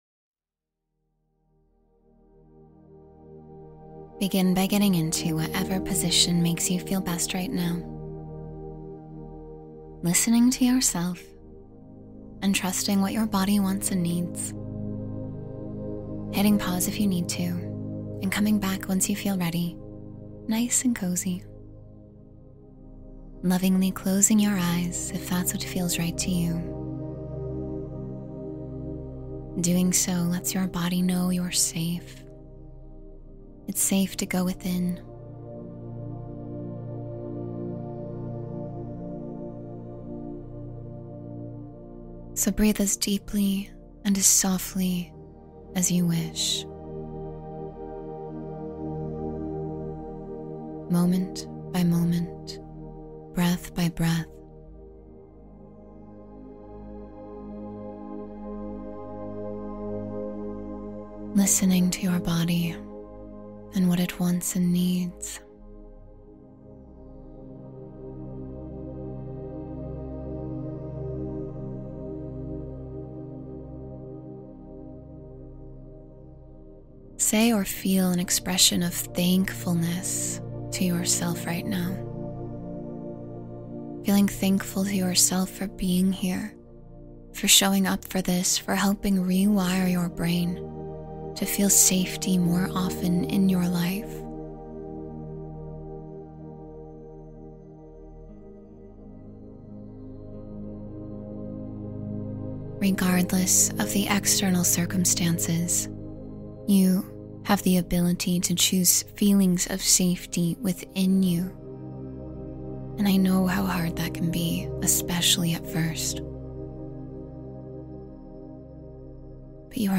Find Strength in Challenging Times — Meditation for Resilience and Calm